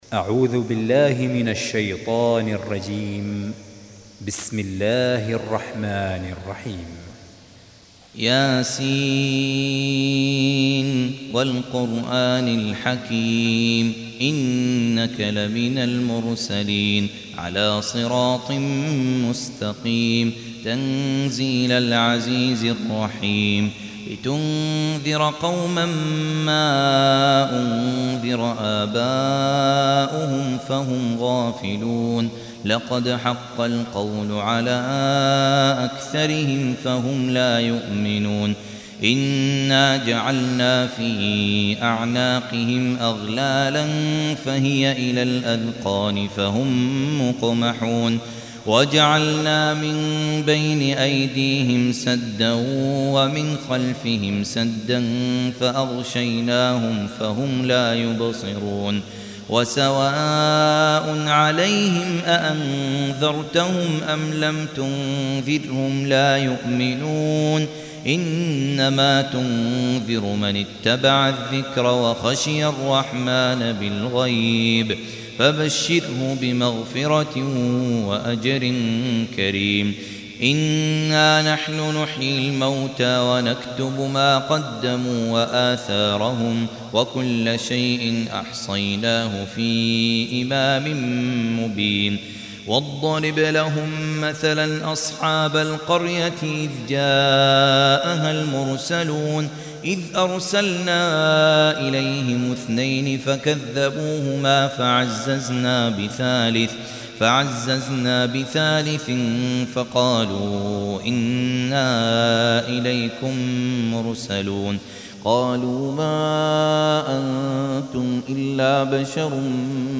Surah Repeating تكرار السورة Download Surah حمّل السورة Reciting Murattalah Audio for 36. Surah Y�S�n. سورة يس N.B *Surah Includes Al-Basmalah Reciters Sequents تتابع التلاوات Reciters Repeats تكرار التلاوات